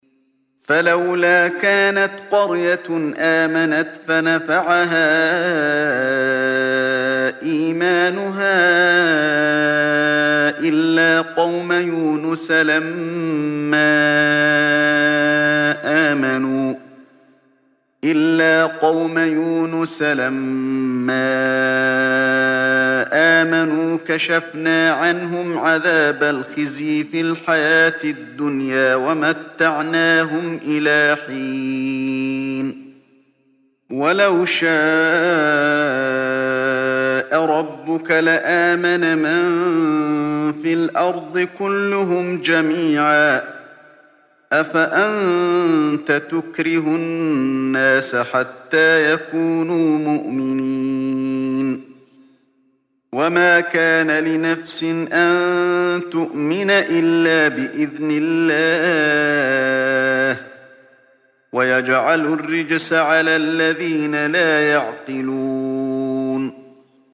Чтение аятов 98-100 суры «Йунус» шейхом ’Али бин ’Абд ар-Рахманом аль-Хузейфи, да хранит его Аллах.